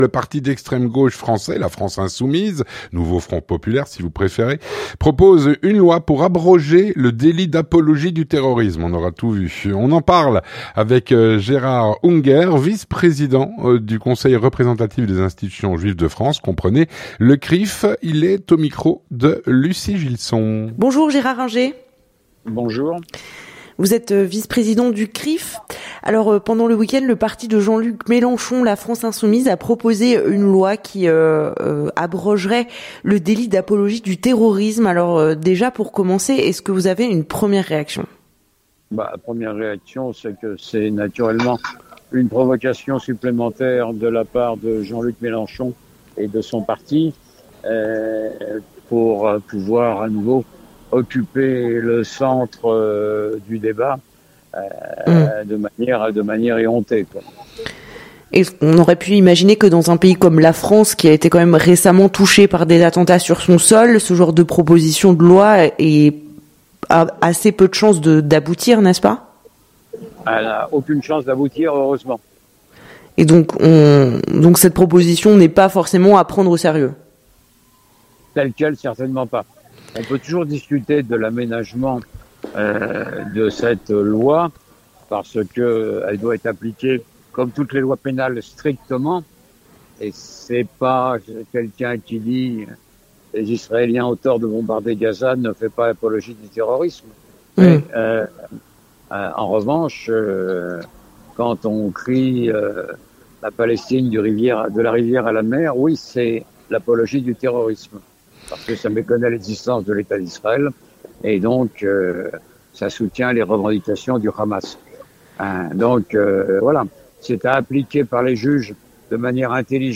L'entretien du 18H - Le parti LFI (La France Insoumise) propose une loi pour abroger le délit d’apologie du terrorisme.